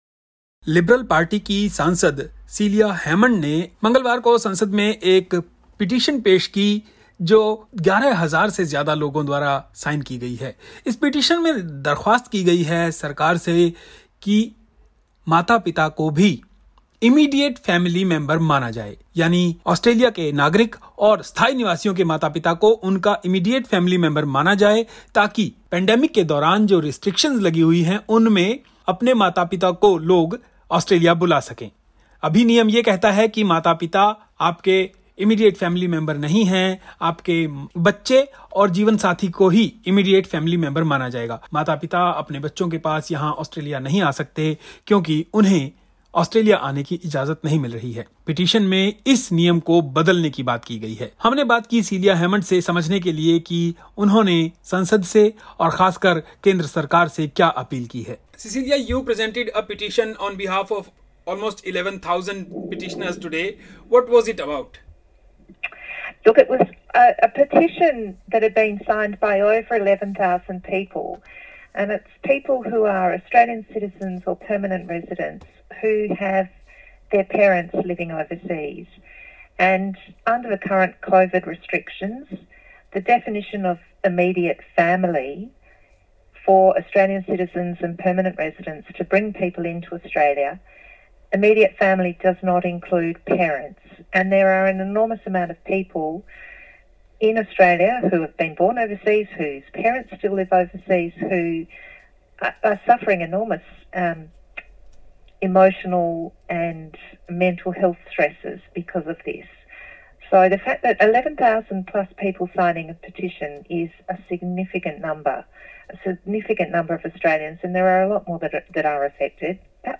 Ms Celia Hammond MP, Member for Curtin, Western Australia speaks on the plight of separated migrant families, listen: